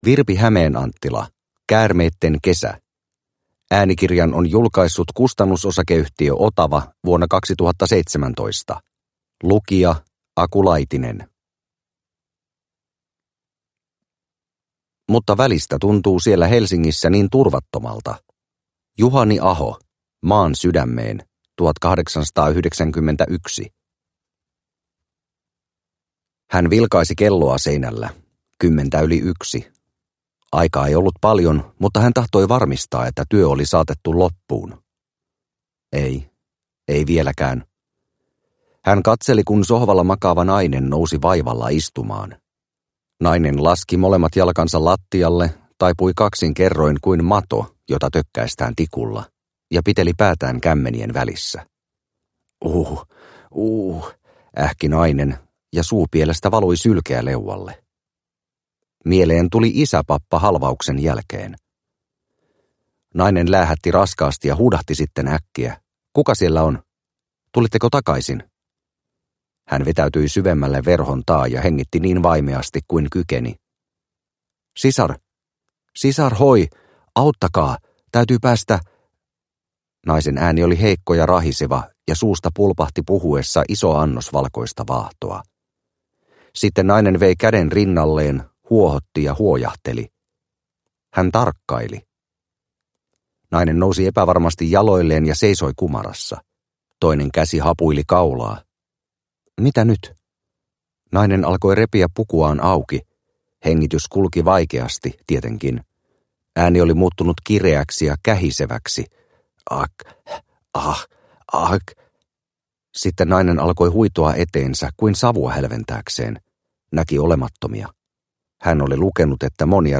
Käärmeitten kesä – Ljudbok – Laddas ner